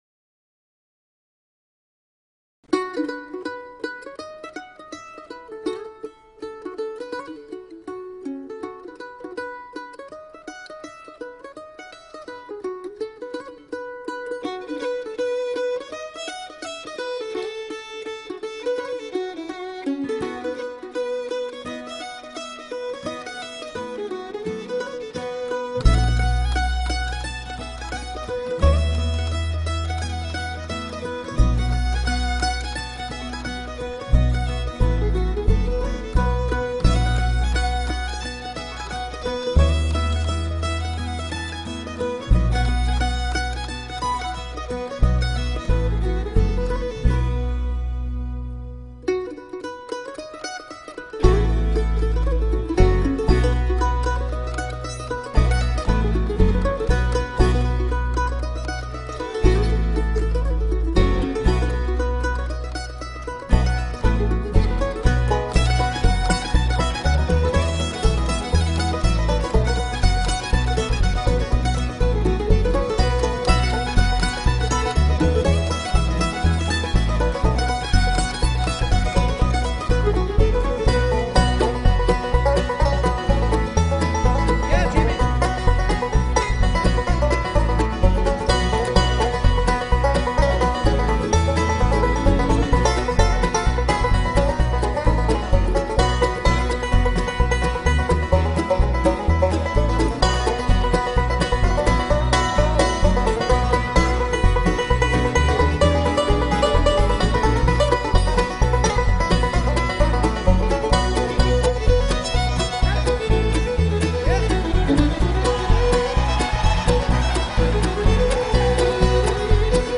ROAD TO SPENCER | MANDOLIN